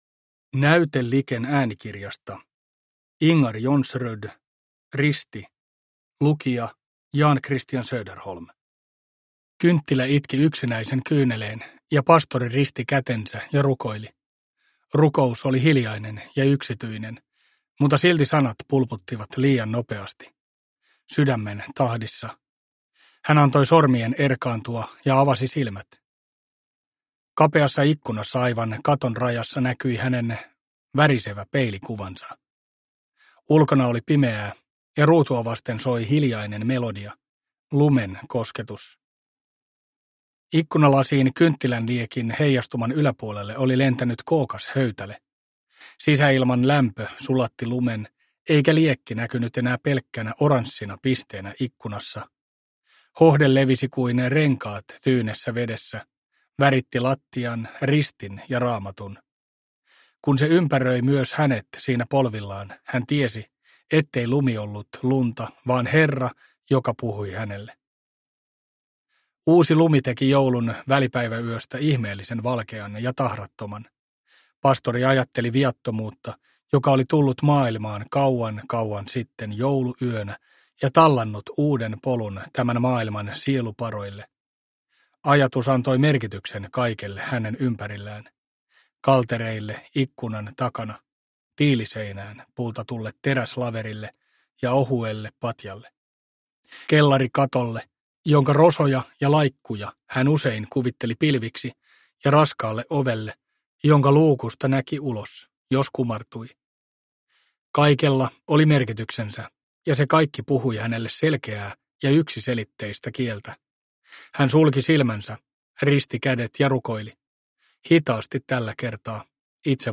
Risti – Ljudbok – Laddas ner